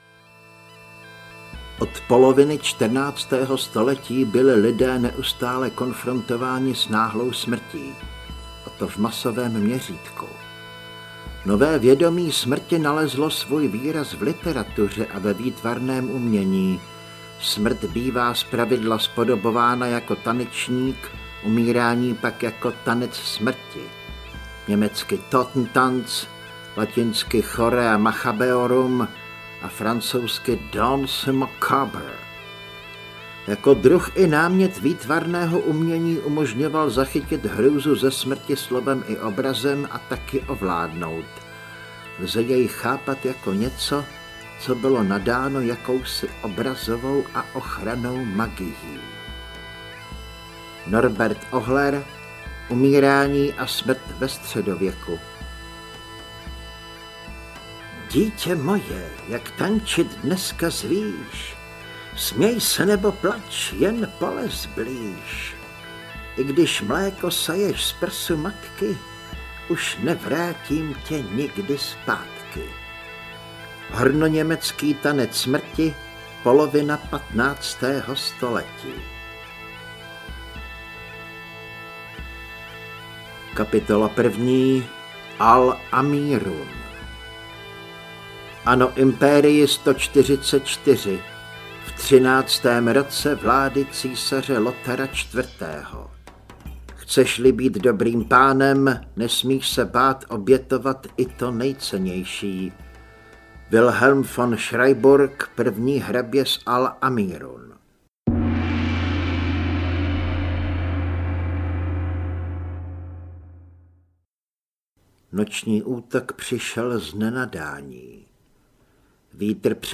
Mrtví muži netančí audiokniha
Ukázka z knihy